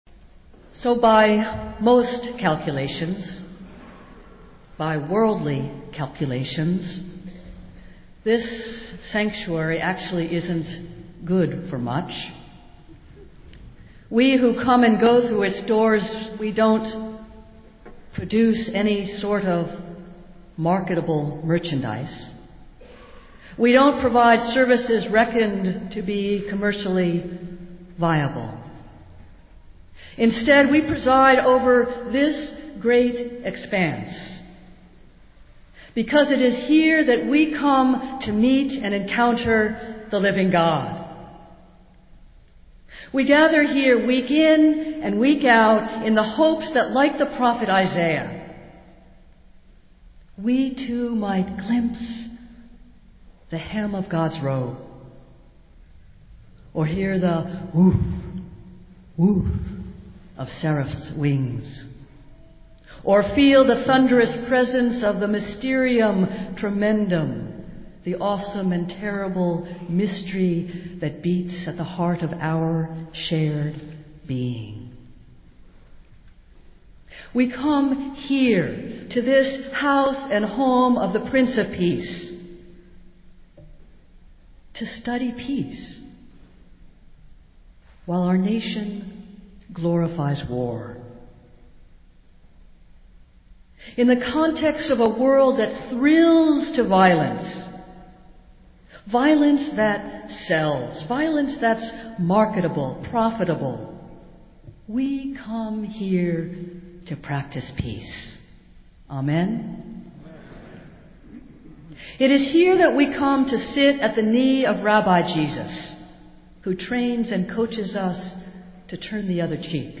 Festival Worship - Second Sunday in Advent